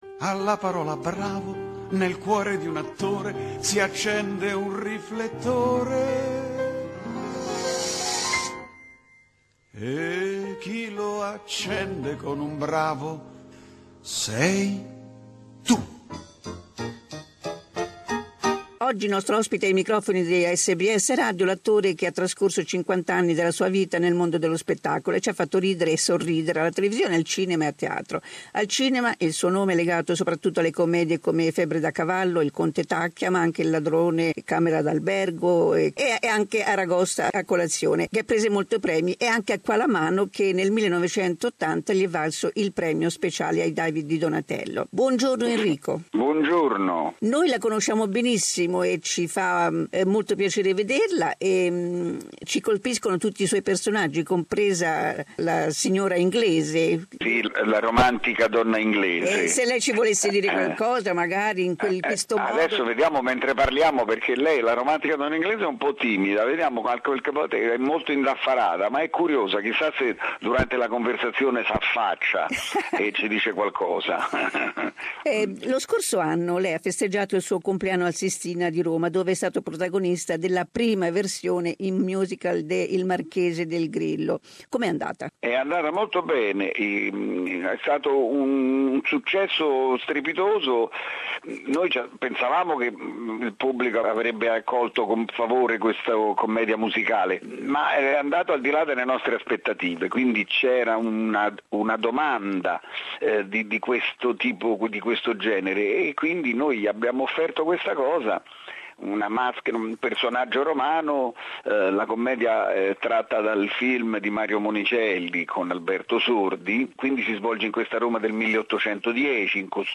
First part of an interview with popular Italian actor, Enrico Montesano.